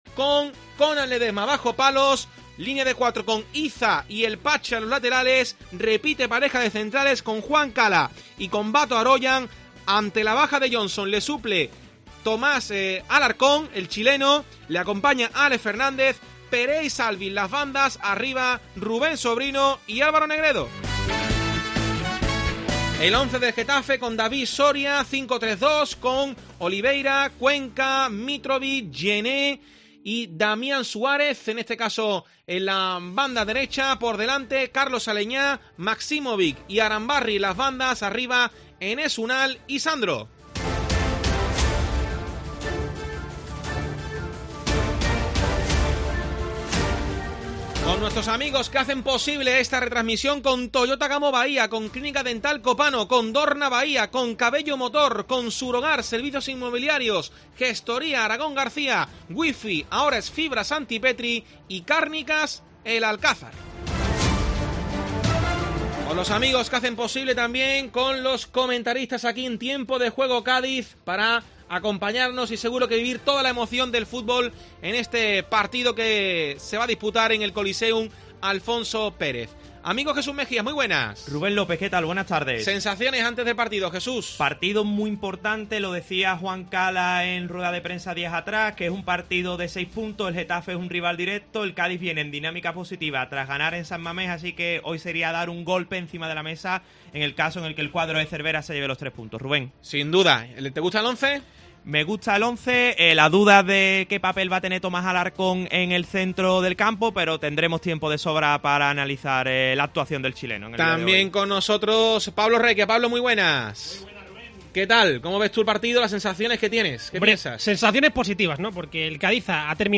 Escucha el resumen sonoro con los mejores momentos de un partido que vivimos durante tres horas en los micrófonos de Tiempo de Juego Cádiz
El resumen sonoro del Getafe 4-0 Cádiz